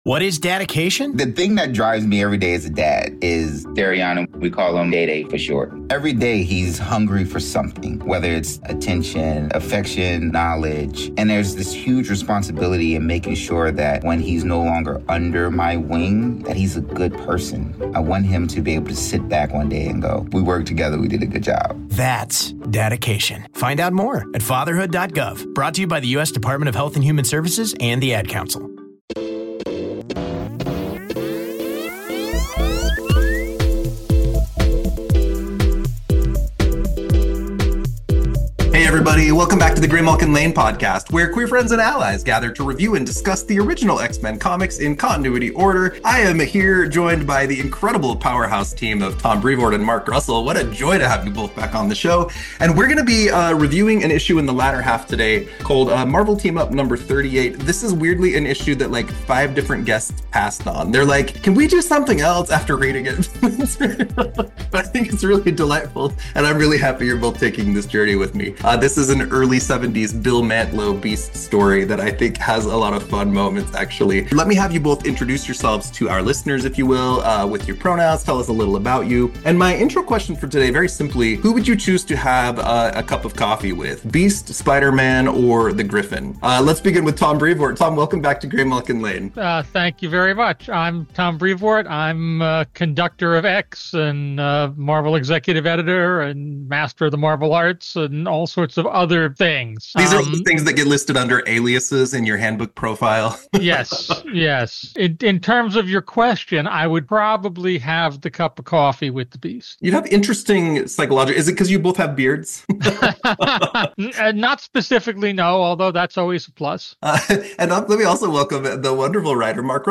But first an interview with i...– Ouça o What If 16: Wolverine Meets Conan!